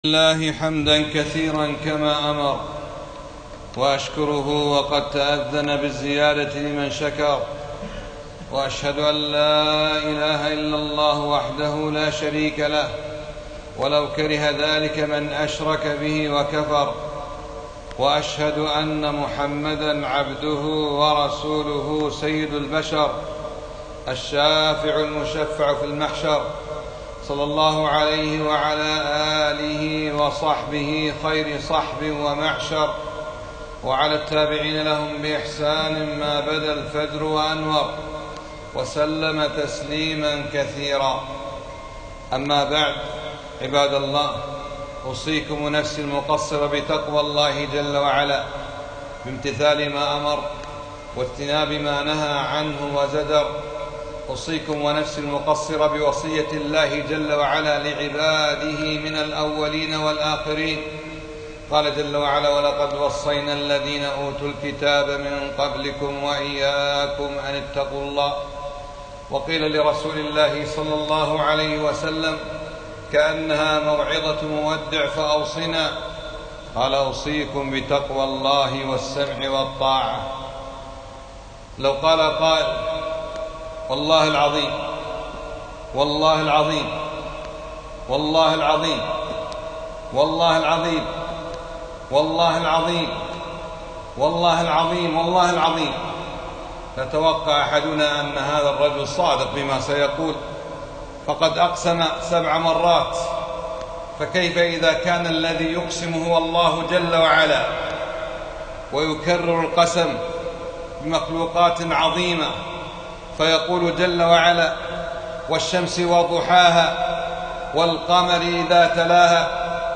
خطبة - تربية النفس على الاستقامة